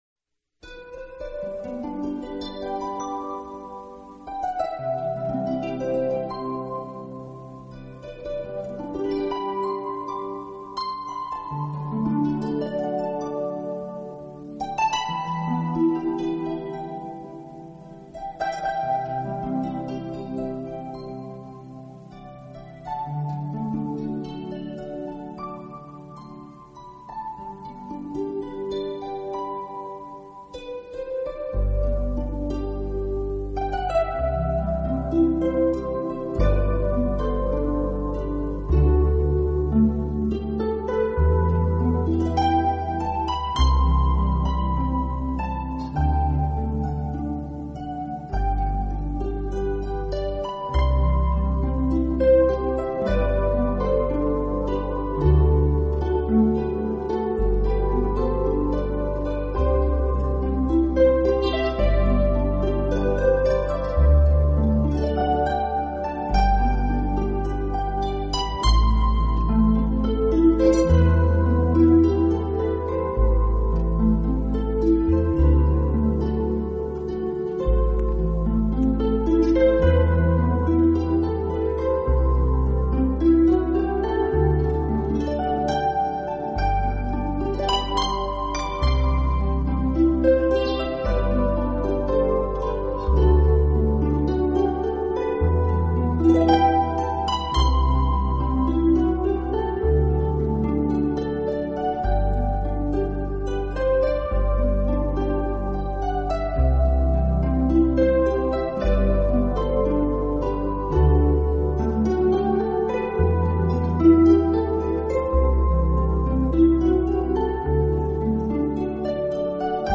音色纯和优美，是抒缓音乐的极品。